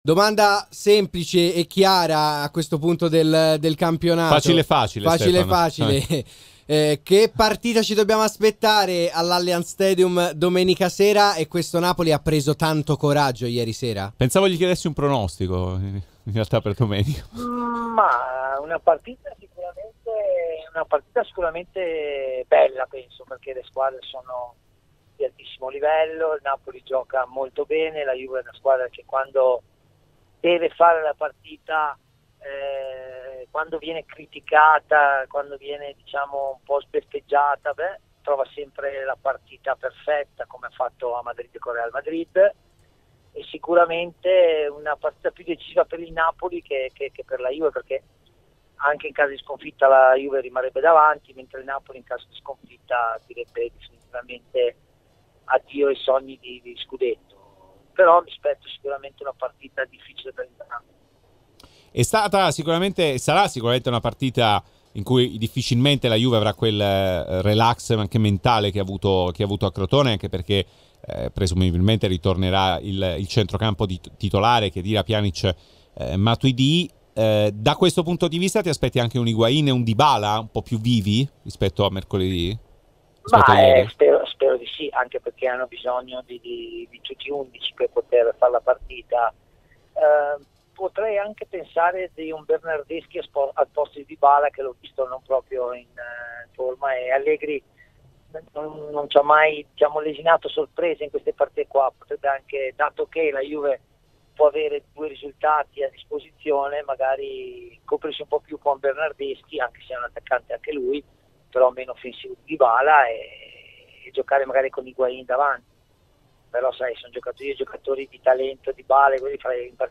Stefan Schwoch, ex centravanti del Napoli, nel suo intervento ad RMC Sport ha commentato così la gara che andrà in scena nel prossimo turno tra la Juventus e i partenopei: "Mi aspetto una partita sicuramente bella perché le squadre sono di altissimo livello.